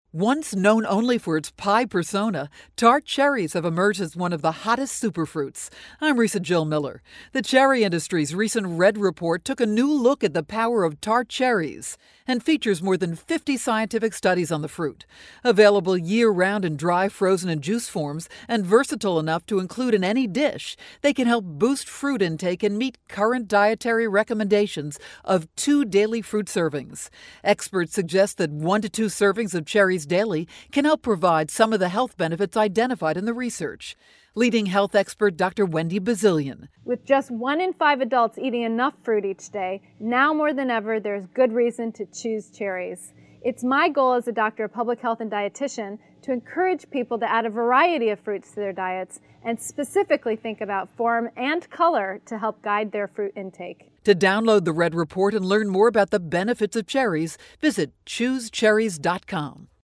February 21, 2012Posted in: Audio News Release